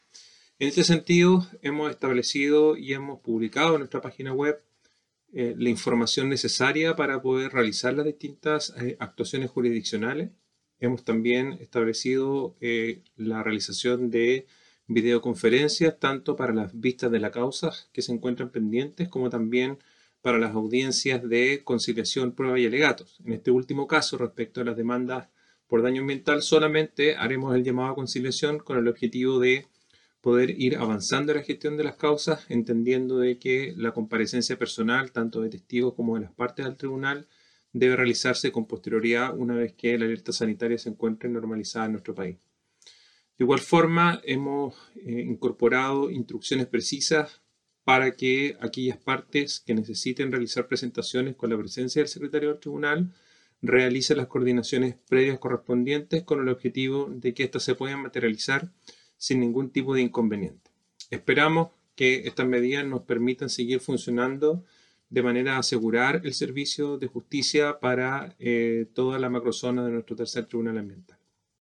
El presidente del Tercer Tribunal Ambiental, ministro Iván Hunter, entregó más detalles sobre la implementación de la medida